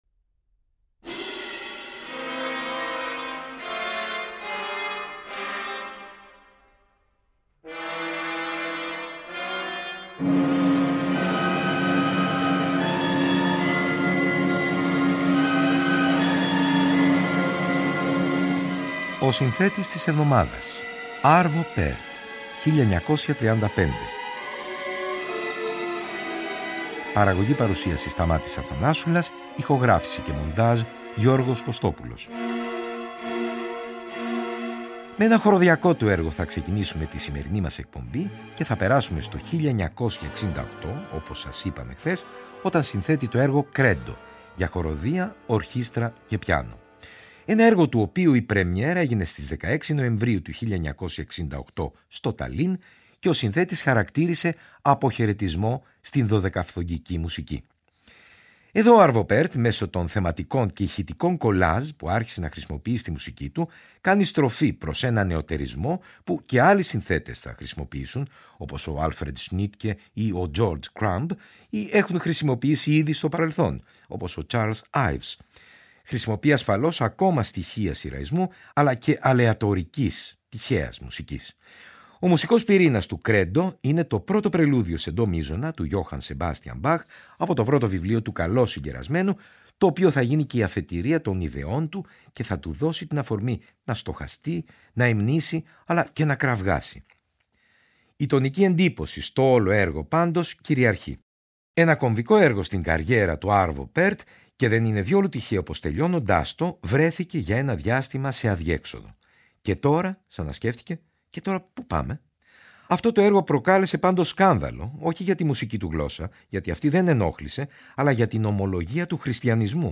Τα έργα του πάνω απ΄ όλα, είναι έμπλεα συναισθημάτων και ιδιαίτερου ψυχισμού. Περνώντας τα χρόνια, φτάνει στην μέγιστη αφαίρεση, από την οποία προκύπτει μία λιτότητα έκφρασης, που μοιάζει να μιλά στον κάθε ακροατή προσωπικά.